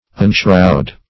Search Result for " unshroud" : The Collaborative International Dictionary of English v.0.48: Unshroud \Un*shroud"\, v. t. [1st pref. un- + shroud.]